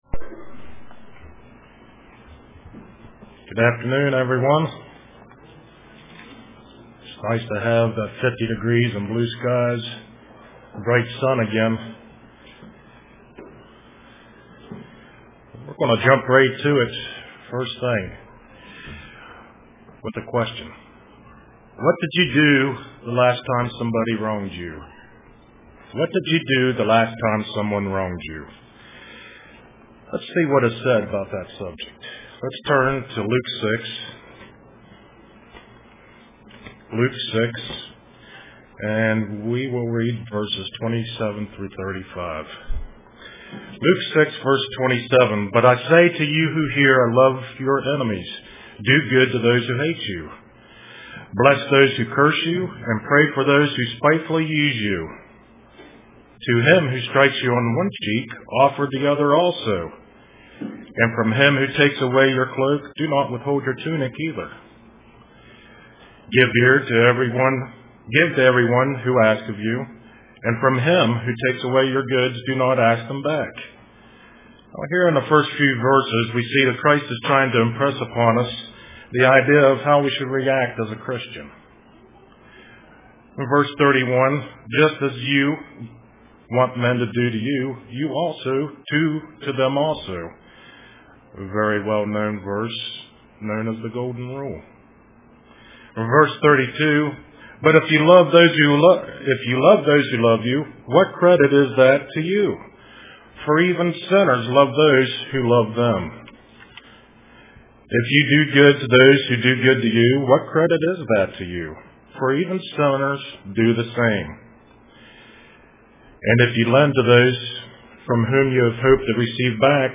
Print When wronged, Do Right Anyway UCG Sermon Studying the bible?